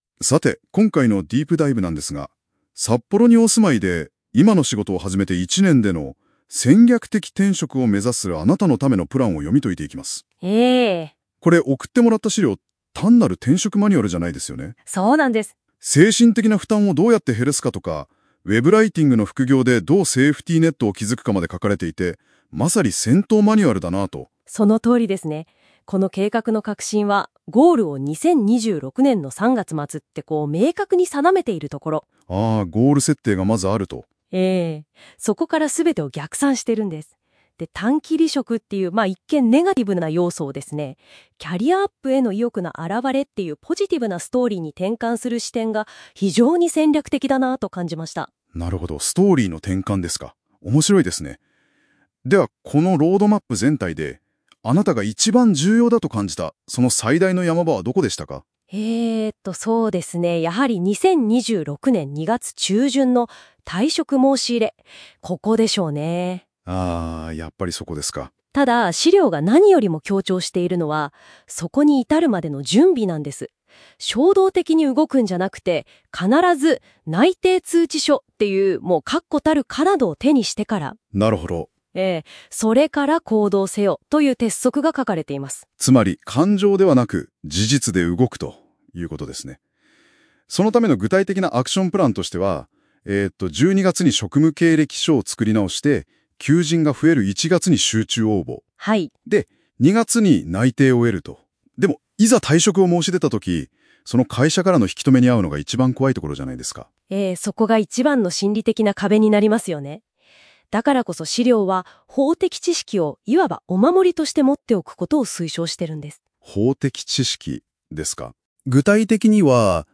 【音声解説】戦略的転職を成功させるための戦闘マニュアル